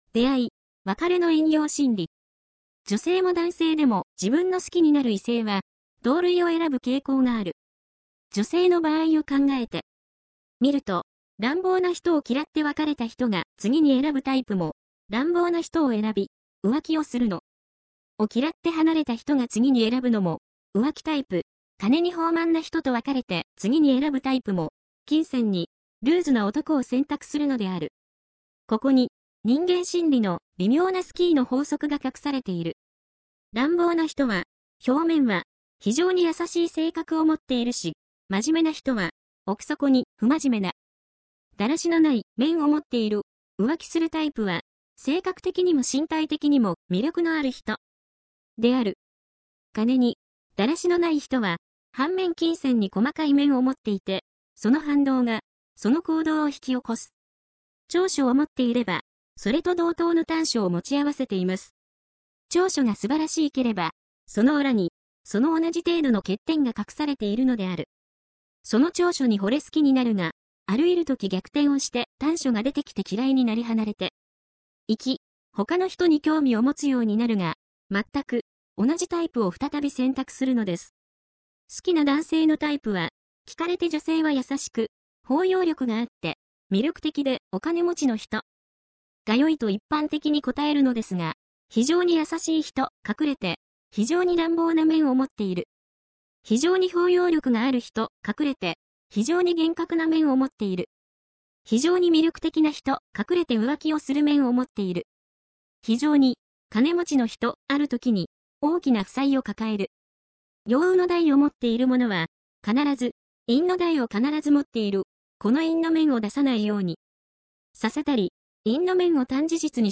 読み上げ音声